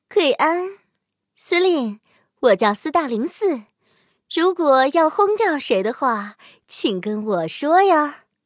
IS-4登场语音.OGG